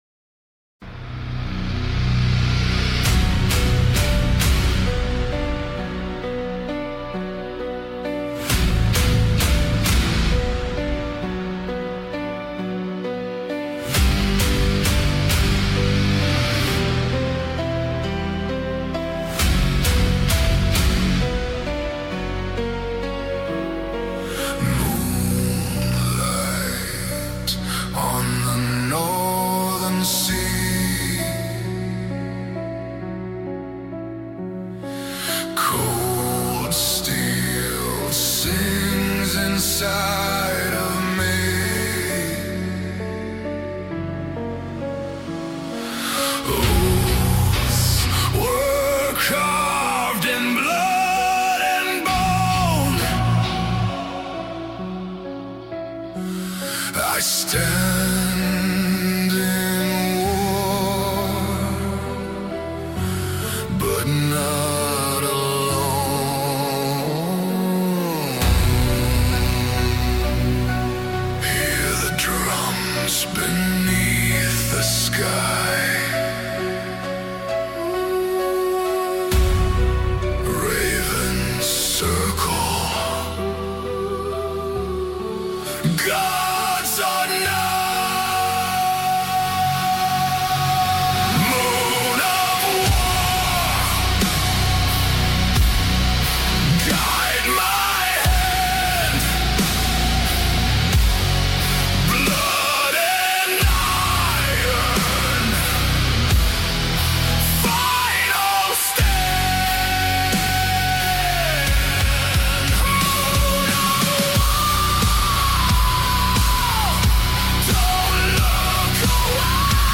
BEETHOVEN Goes To War – Moonlight Sonata - {Viking Metal} AI
little slow but still very good
although the voice does vibrate a bit more and sounds a bit more uncanny valley